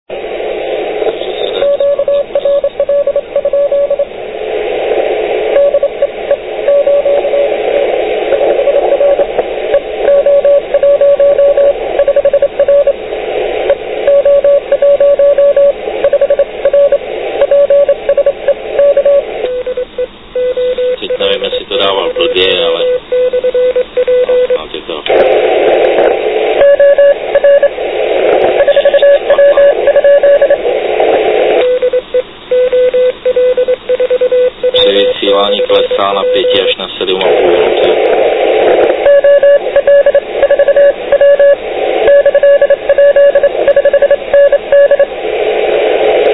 Možná jim byla i zima a tak za všechny snad záznam blíže nespecifikované stanice:
Všimněte si, že v přírodě je minimum městského rušení. Poznáte to podle toho, že Smetr ukazuje na nulu ( v mém QTH trvale S5).